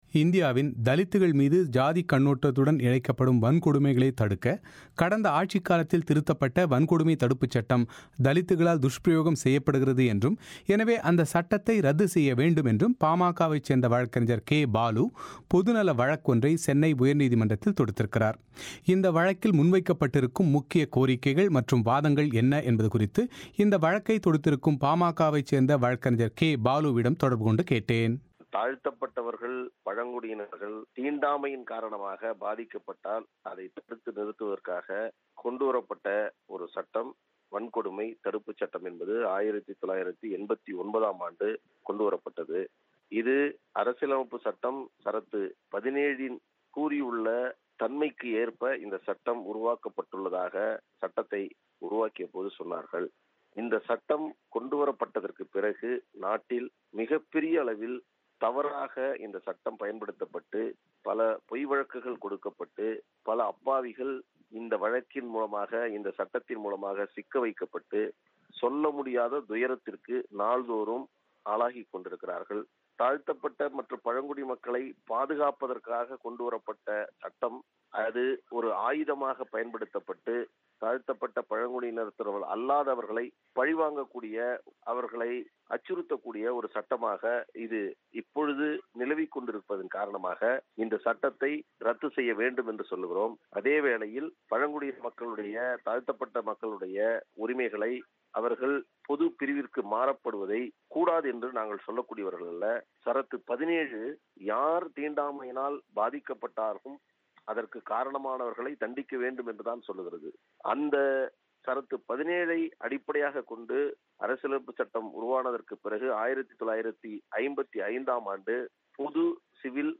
பிபிசி தமிழோசைக்கு அளித்த செவ்வியையும்